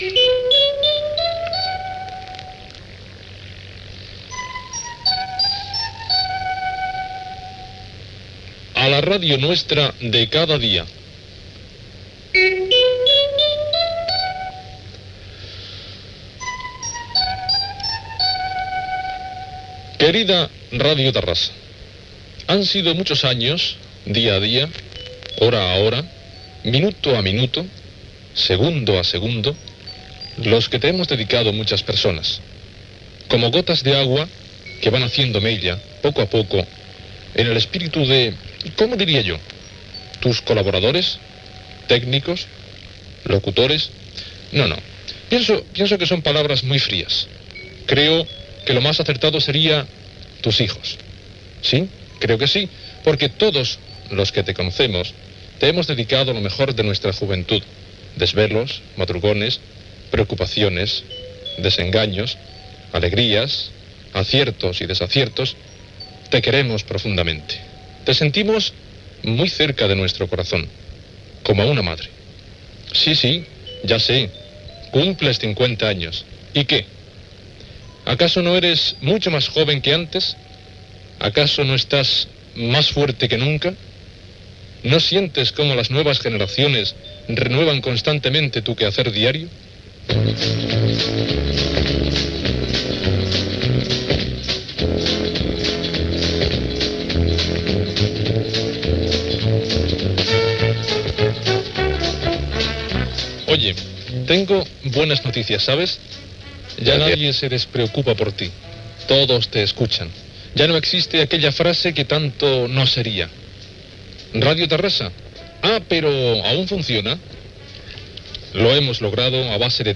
Sintonia de la ràdio